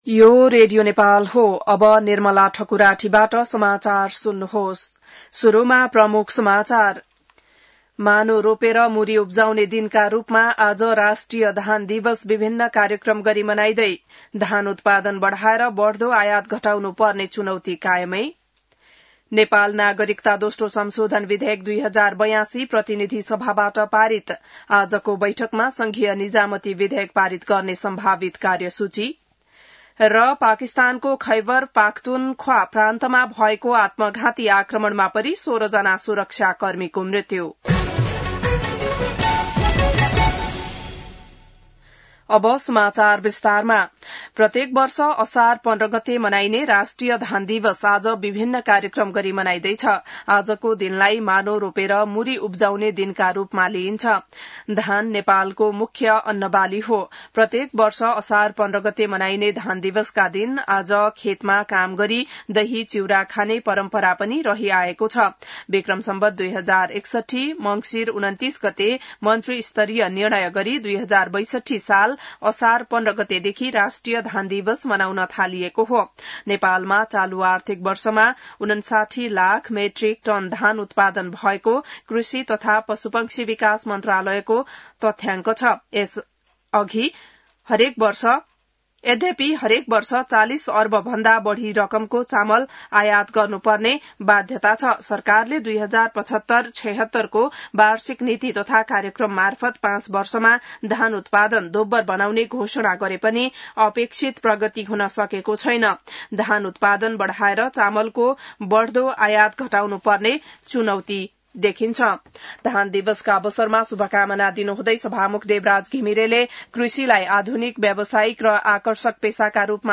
बिहान ९ बजेको नेपाली समाचार : १५ असार , २०८२